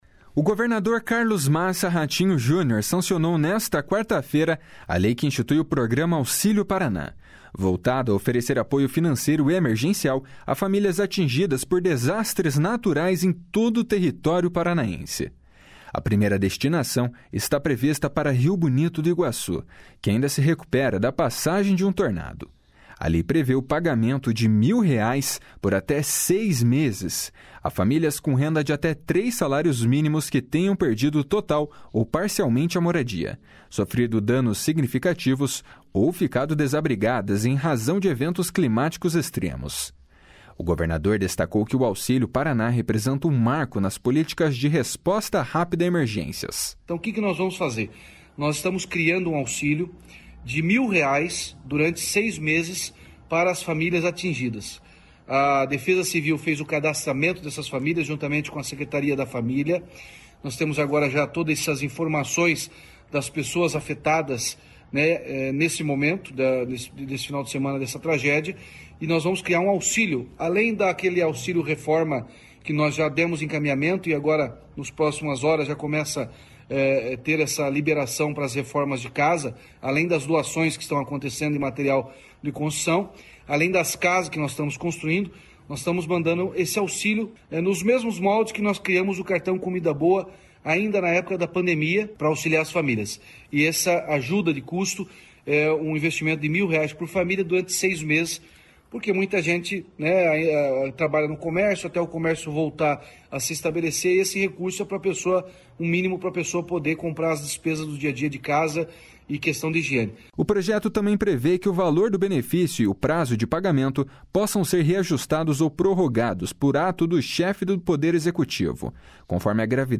// SONORA RATINHO JUNIOR //